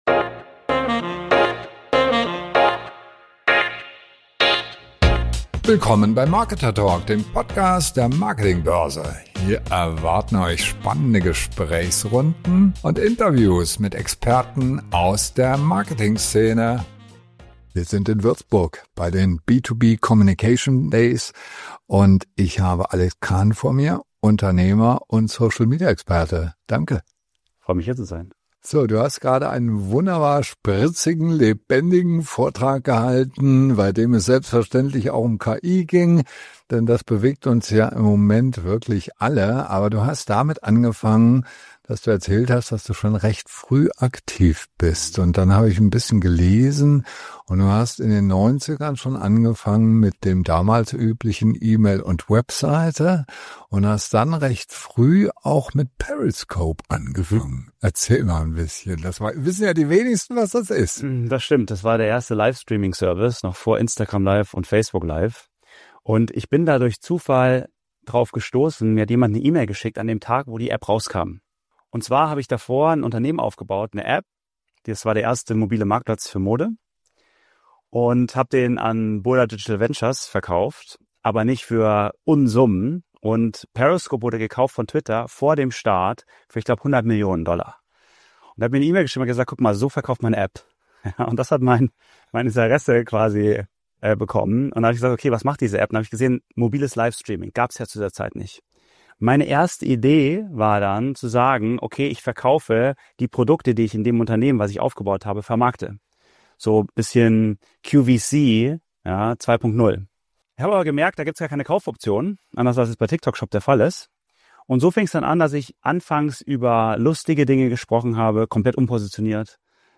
Von Periscope bis KI: Wie du mit Authentizität und Technologie in B2B-Marketing durchbrichst. Ein Gespräch über die Strategien, die funktionieren – und warum Corporate Influencer deine nächste Geheimwaffe sind.